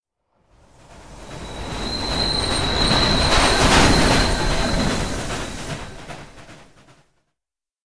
CBHQ_TRAIN_pass.ogg